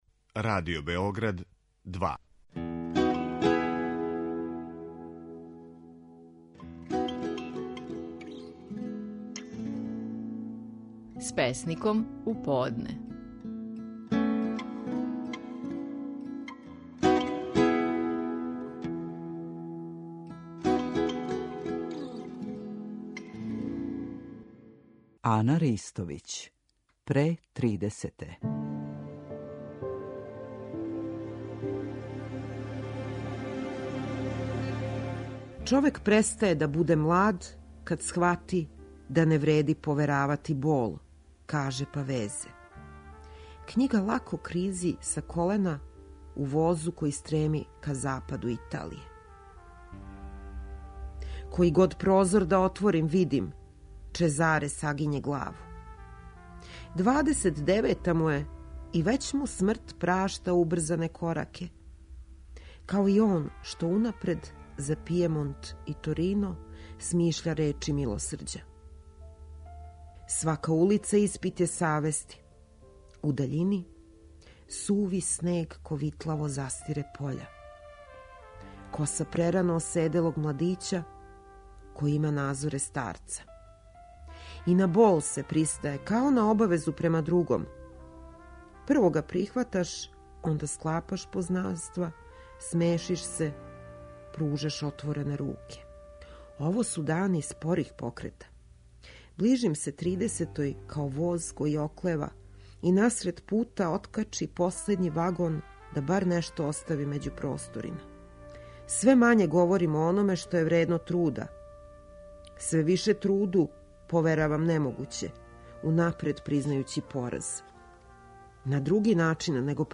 Стихови наших најпознатијих песника, у интерпретацији аутора.
Ана Ристовић казује песму „Око тридесете".